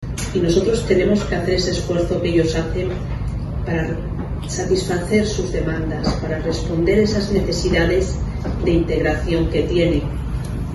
El pasado 3 de octubre se produjo el acto de colocación de la primera piedra de un nuevo gran edificio que se convertirá en el mayor centro de atención a la discapacidad de toda la región.